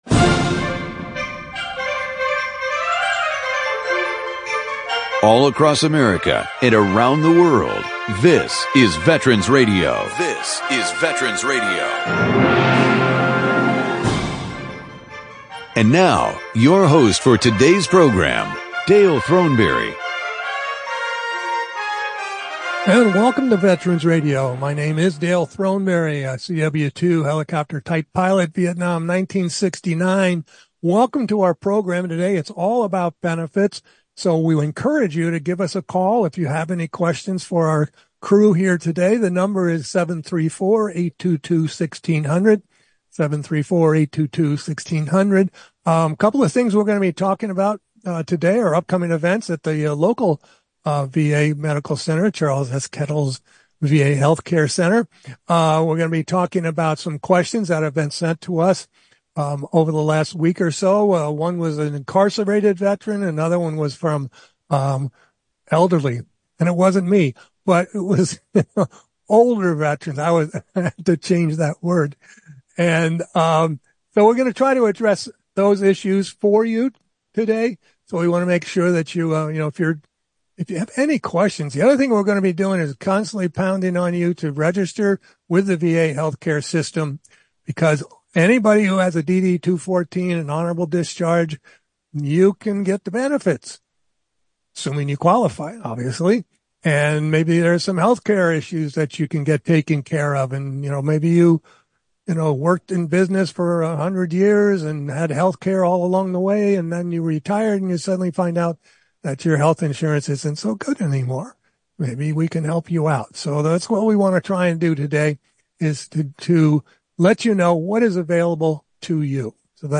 Tune in to our monthly veterans benefits hour where we talk all things benefits! Call with questions for our panel of benefits experts.